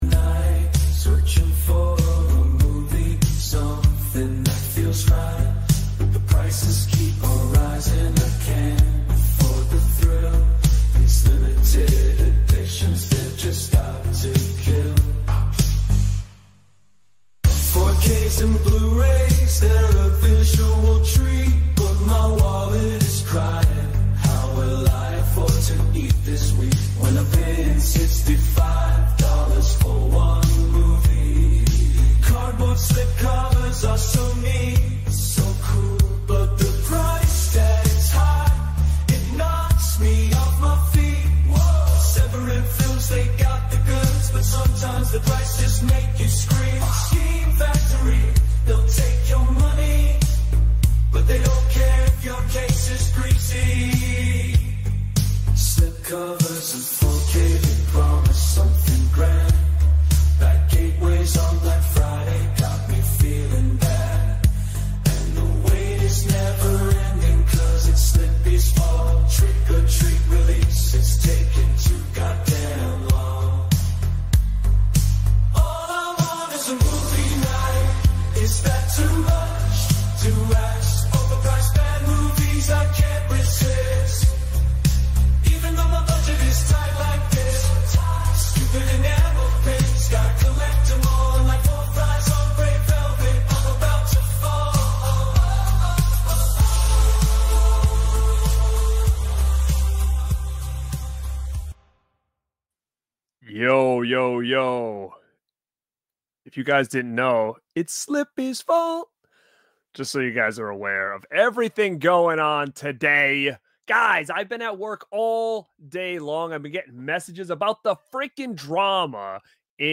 The Original Horror Radio talk show, with interviews, reviews, and more.